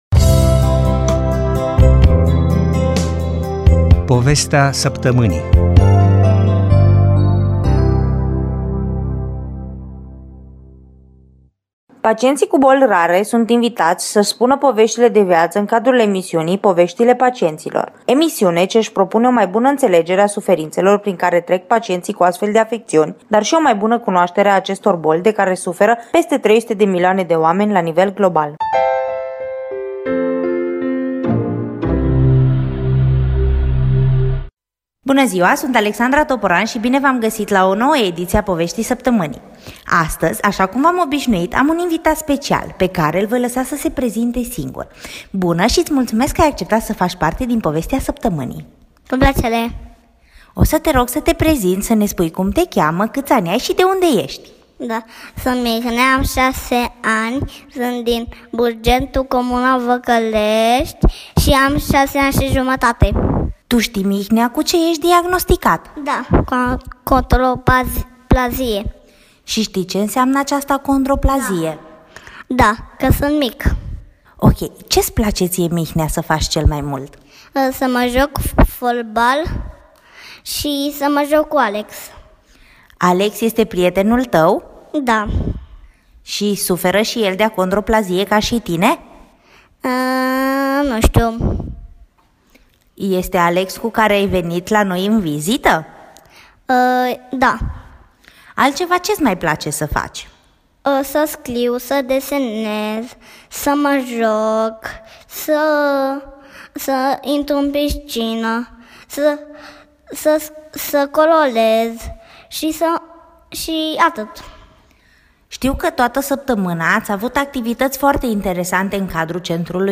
Specialist la Microfon - Interviu